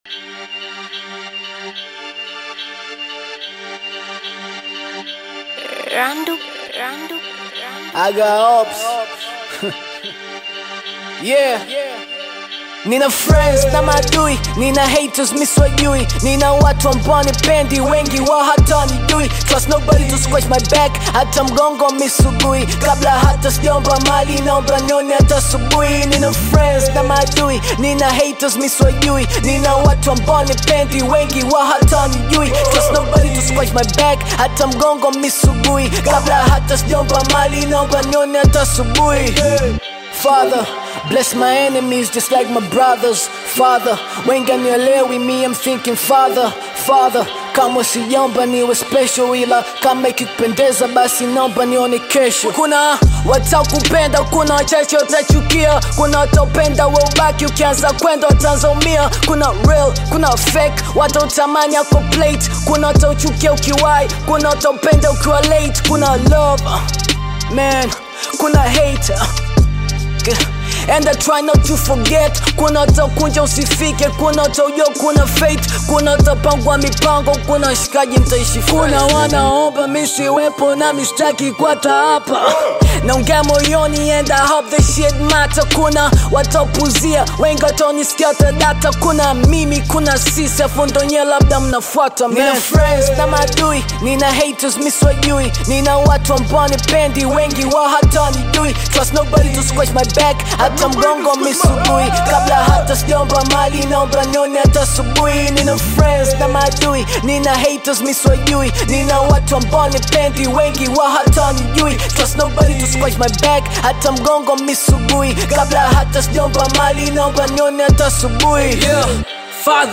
Bongo Flava Hip Hop
Bongo Flava